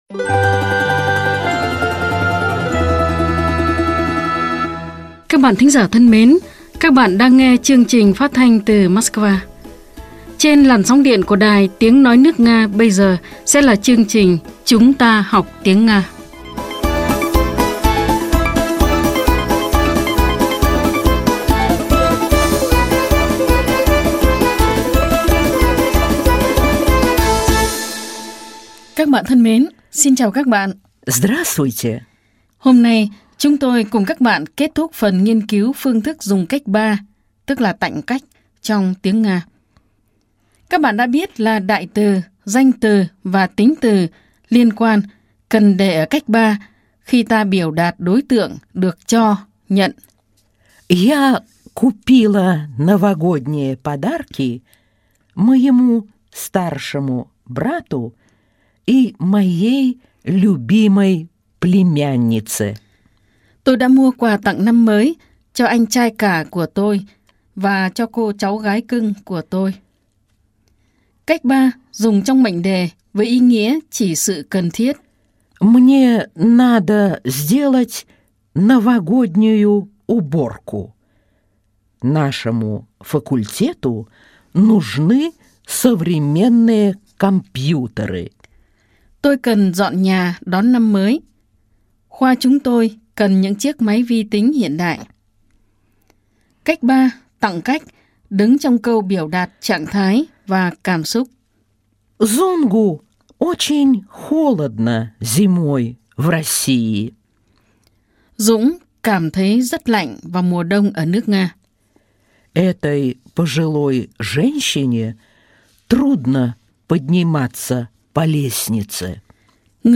Bài 70 – Bài giảng tiếng Nga - Tiếng Nga cho mọi người